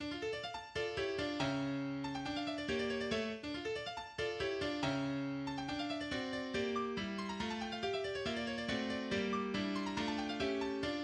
その他独奏ピアノ
ロベール・カサドシュ(P)、ジョージ・セル指揮クリーヴランド管弦楽団《1956年11月10日録音》
ニ短調 - ニ長調、2分の2拍子、ロンドソナタ形式
一転変わって、激しい曲想でピアノの分散和音のソロから始まる。そしてピアノのソロの後は弦楽器でピアノの旋律を一斉に奏する。